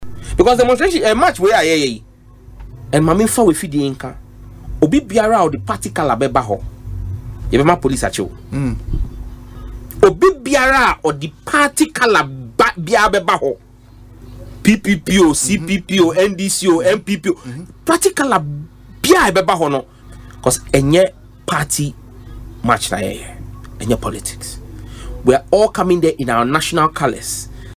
Speaking on Asempa FM’s Ekosii Sen programme Tuesday he said the Police will be given the authority to deal with anyone who joins the demonstration in political party colours.